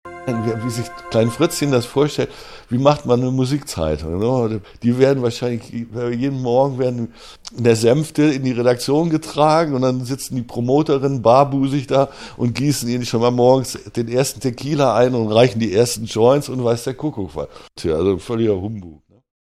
Dazu führte er zahlreiche Interviews mit der letzten Generation der SOUNDS-Redakteure durch und schnitt die Interviews entlang der Geschichte der SOUNDS zusammen.